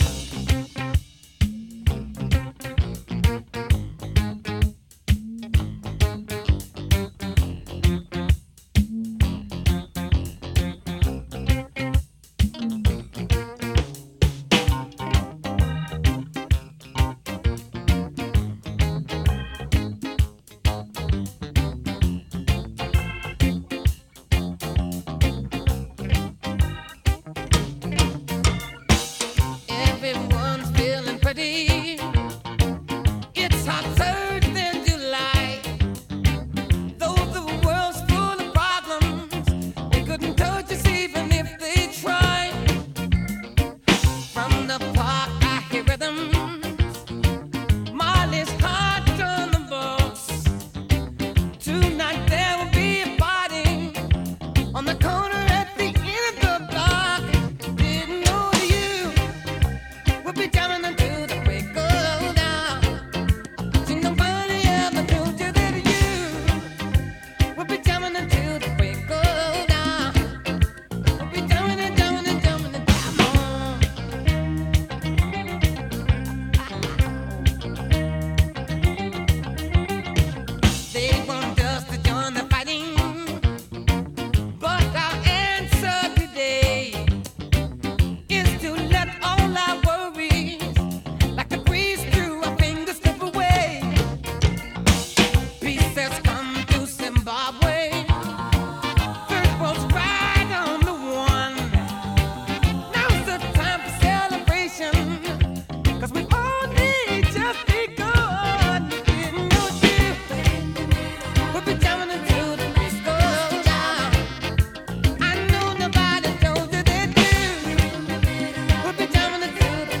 BPM129-133
Audio QualityMusic Cut
CommentsUPDATE (11/23/25): made audio louder